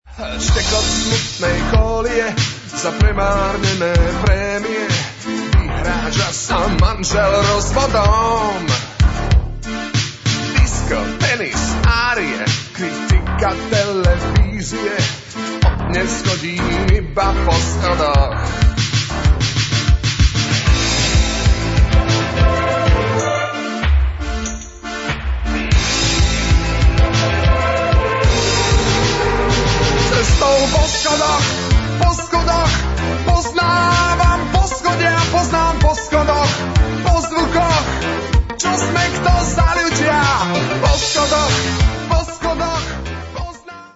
vocal
keyboards, guitars
background vocal, keyboards
drums
synthesizers